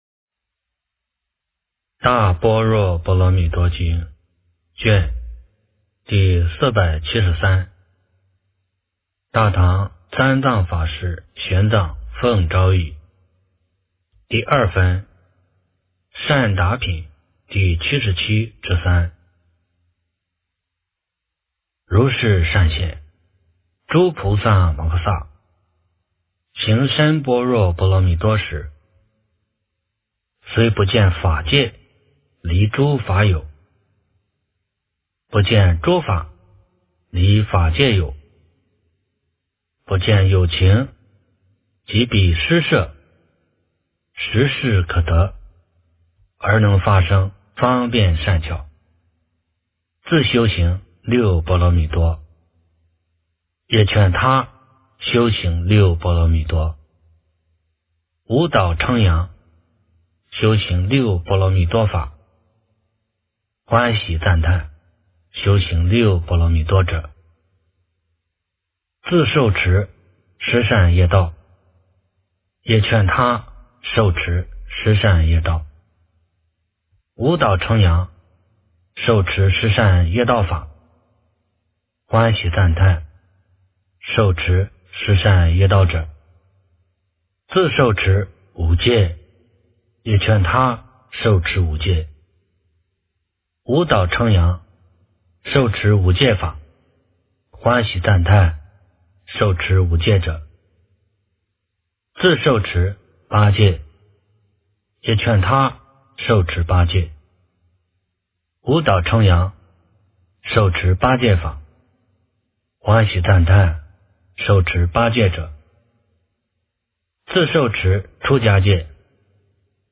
大般若波罗蜜多经第473卷 - 诵经 - 云佛论坛